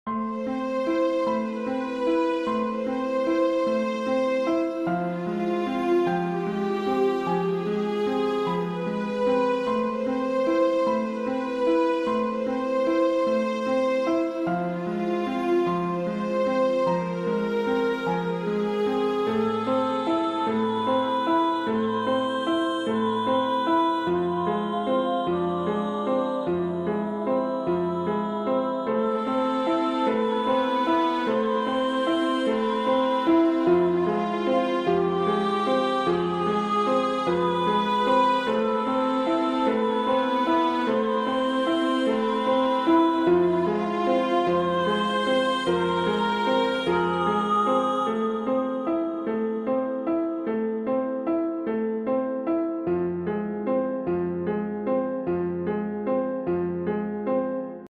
Film Music